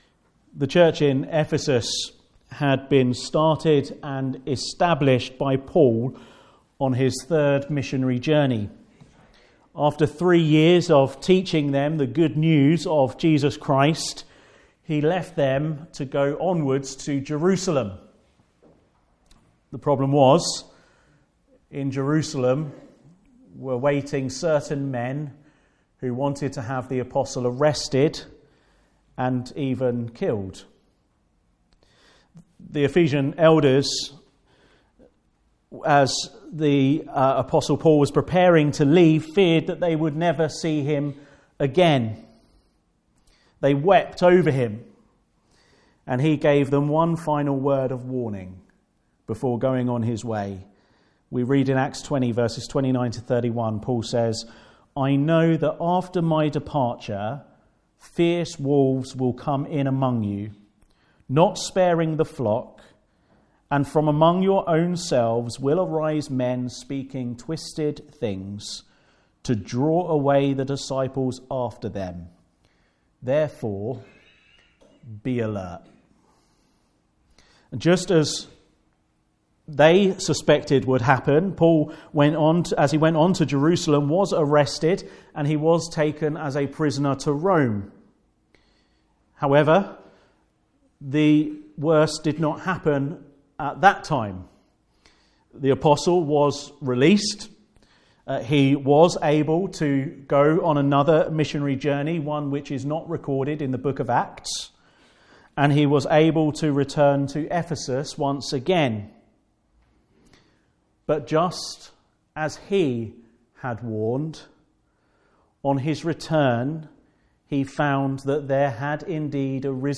Passage: Isaiah 2: 1-11 Service Type: Afternoon Service